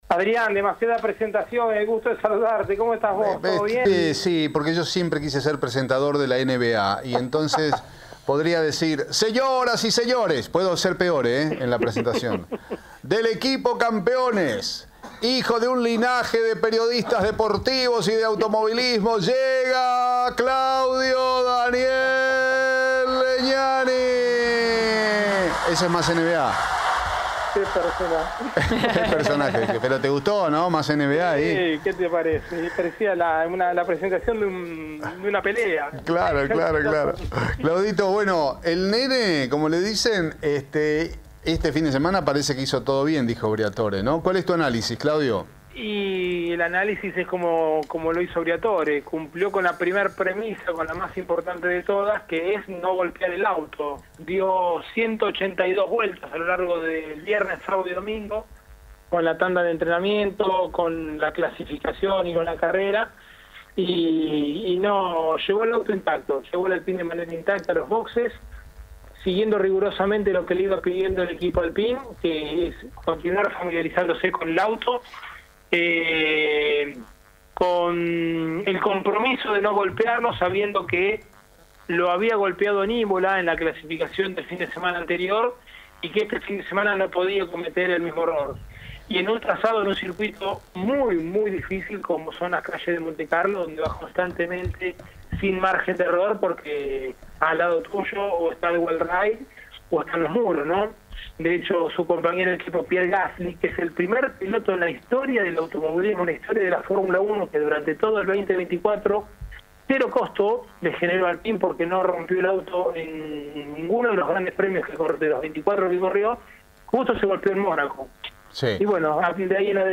ENTREVISTA A RICARDO DANIEL BERTONI